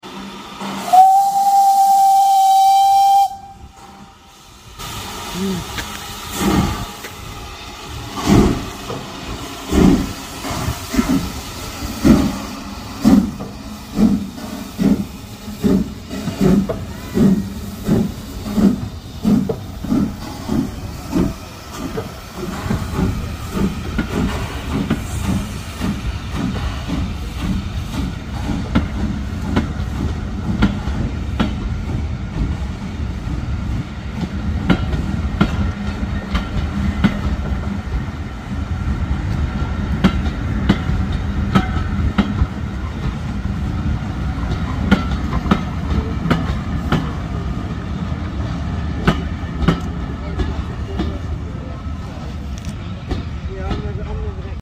H2 32424 'Beachy Head' departing